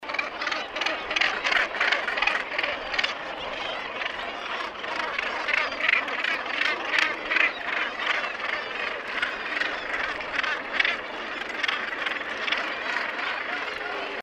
Calls 2.mp3